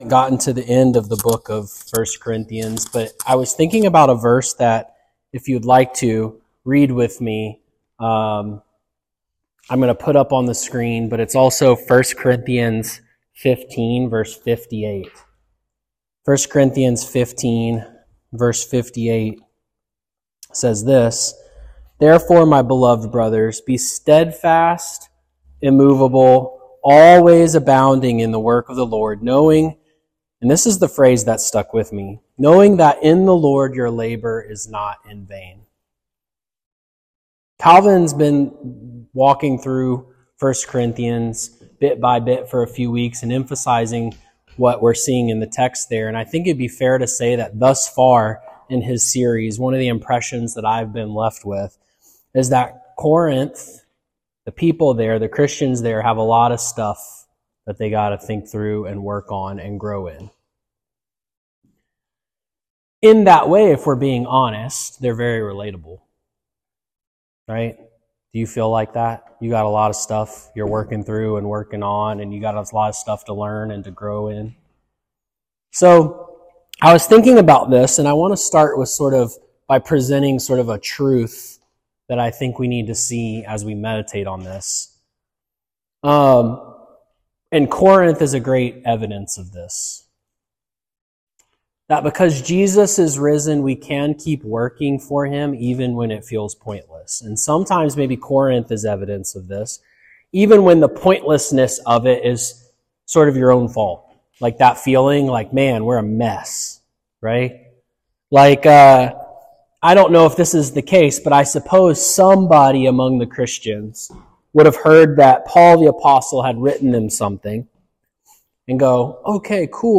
In this sermon from 1 Corinthians 15 and Judges 6, we look at a fearful Gideon, a messy church in Corinth, and the risen Christ who guarantees that no obedient labor in Him is ever wasted. Be encouraged to keep fighting sin, serving in a small church, and bearing everyday witness.